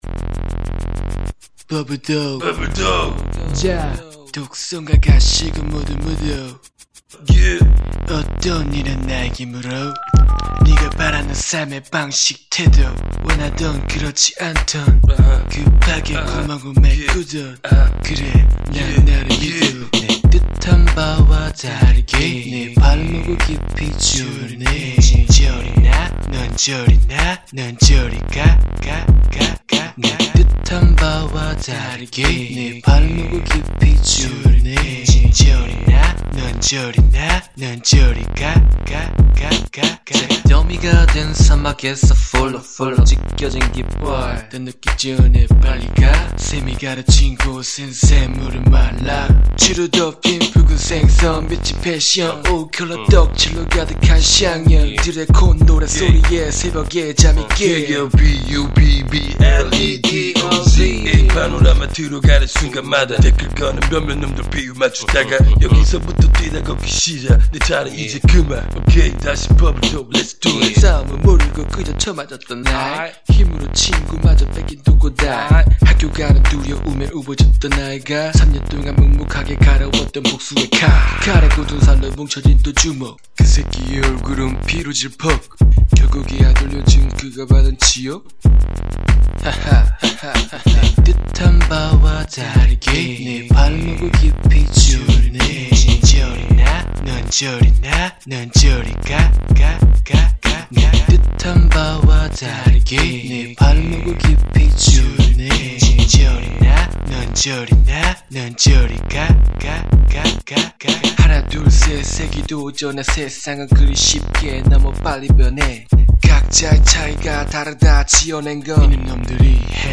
[국내 / REMIX.]
2 Thumb Up 미디어 듣기 코러스를 간만에 이사람 저사람 따라 하면서 녹음을 허니 참 들으면서 재미있기도 하고 쑥스럽기도 허네요 녹음 한지 오래 된거라 가사를 같이 올리려고 찾아보니 없네요 여튼 상처 받을지 모르니 욕은 삼가해 주세요 ^^